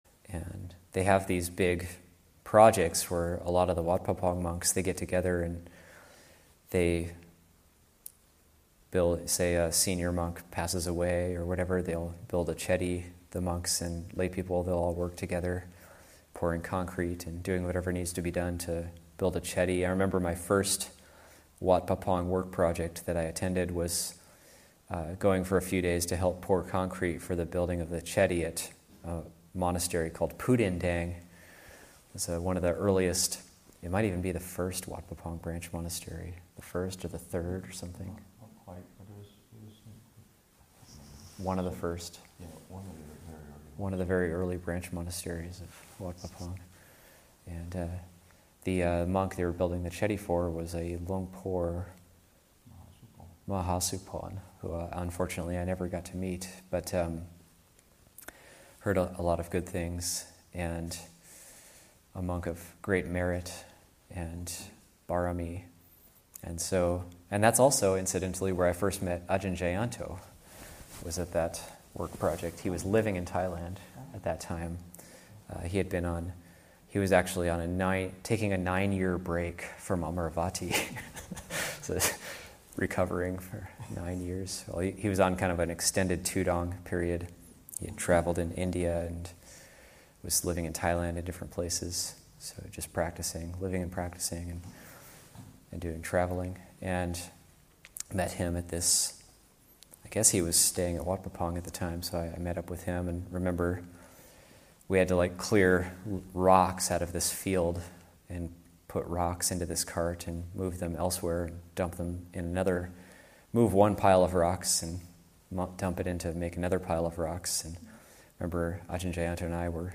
Abhayagiri 25th Anniversary Retreat, Session 11 – Jun. 12, 2021